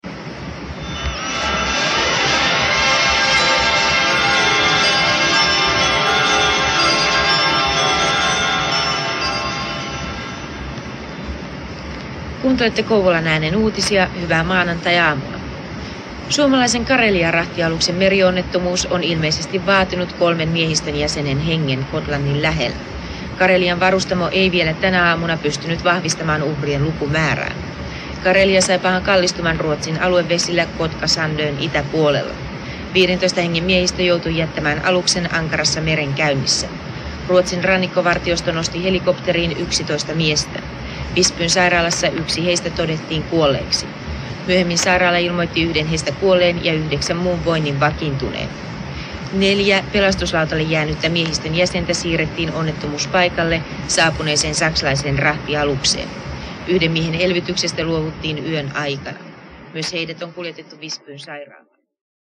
Kouvolan Äänen uutiset 1986.